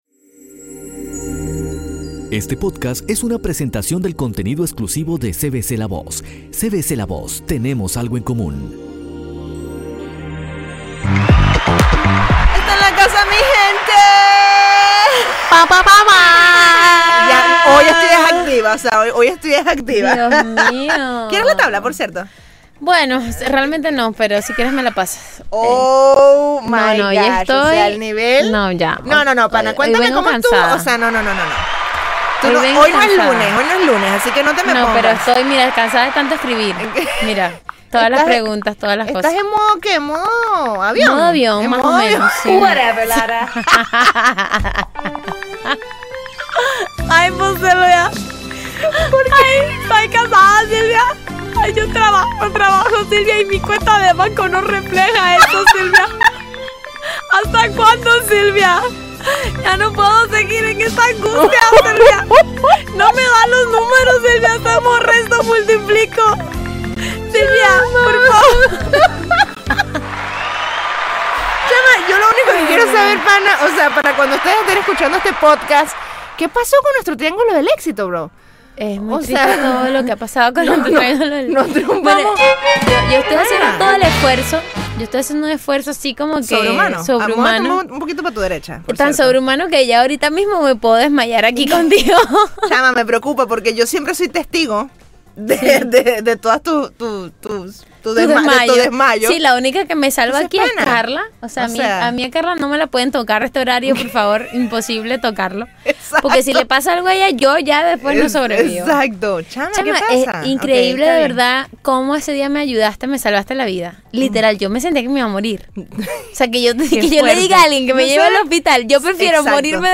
Pasen adelante a reír (y reflexionar al mismo tiempo) en esta conversación honesta entre dos amigas que están aprendiendo a pedir ayuda.